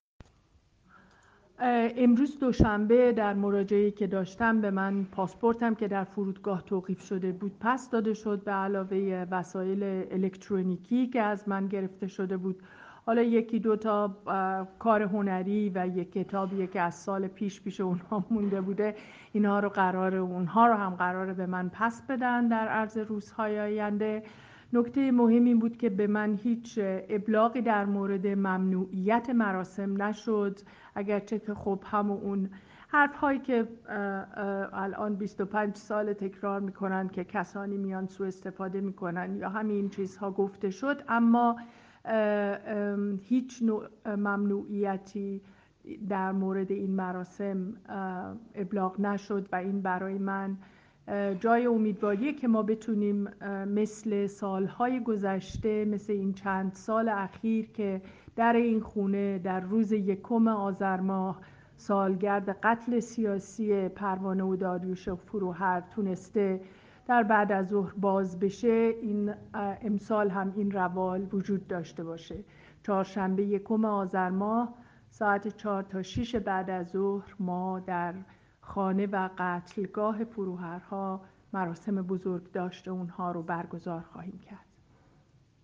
پرستو فروهر، نویسنده، هنرمند و فعال حقوق‌ بشر که در آلمان زندگی می‌کند، در گفت‌وگو با رادیو فردا خبر داد که گذرنامه و وسایل الکترونیکی او که در بدو ورودش به فرودگاه تهران توقیف شده بود، به او بازگردانده شده است.